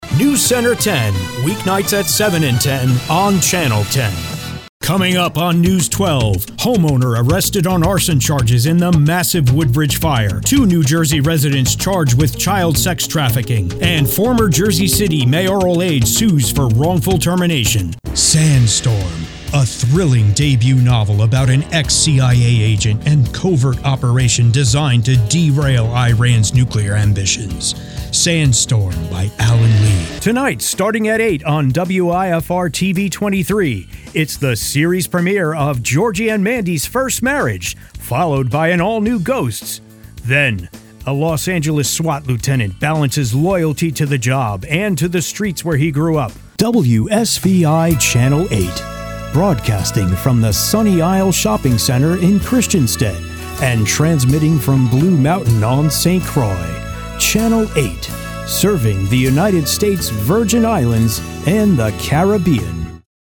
Promo montage for radio and TV